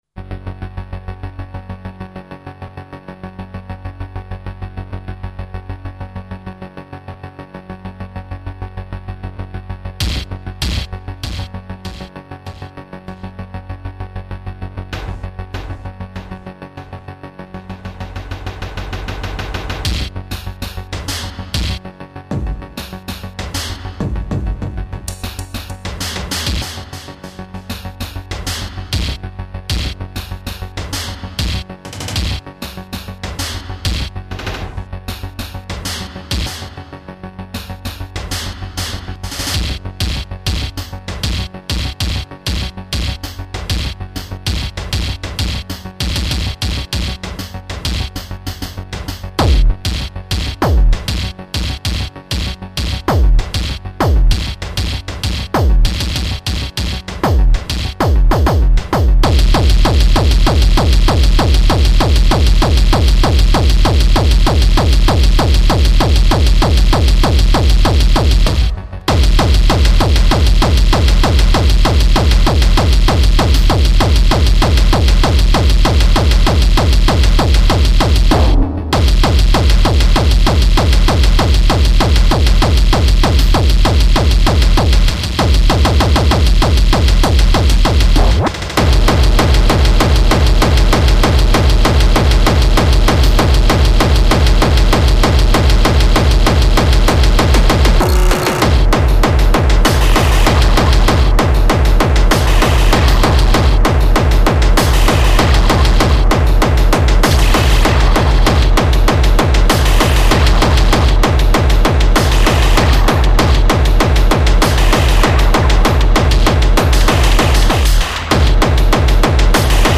195 BPM